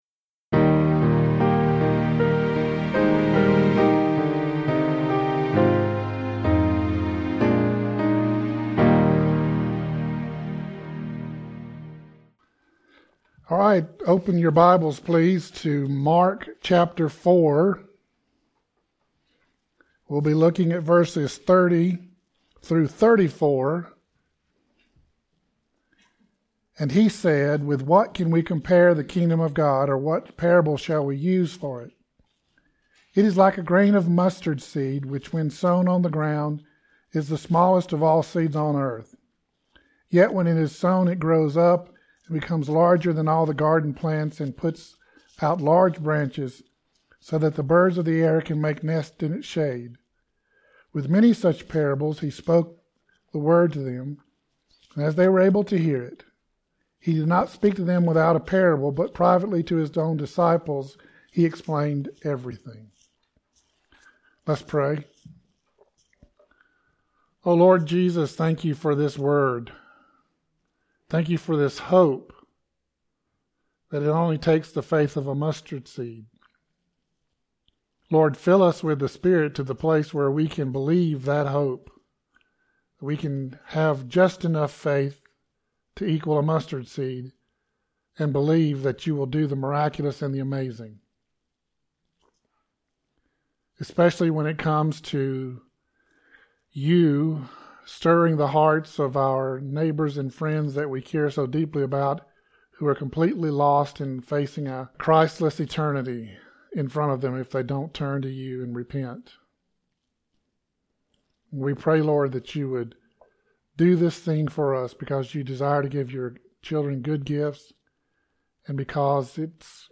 1 Sermon - Just A Mustard Seed 27:57